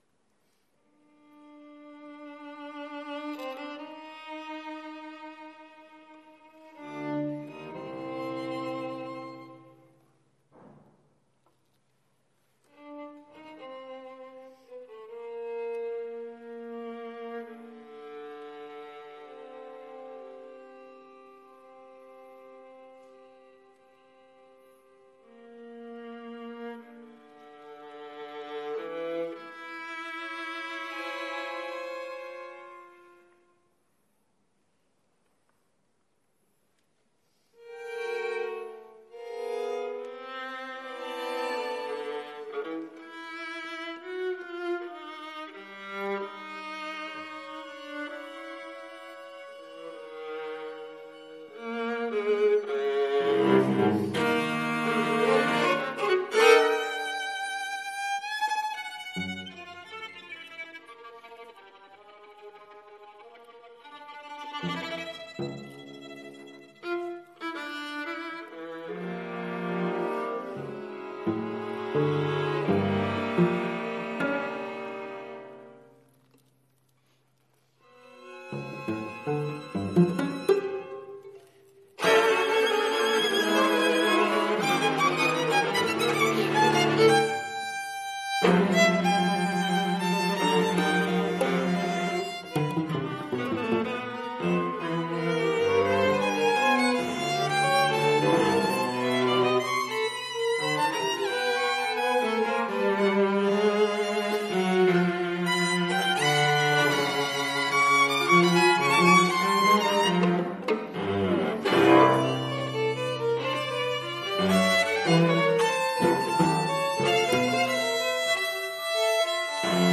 Robinson College Cambridge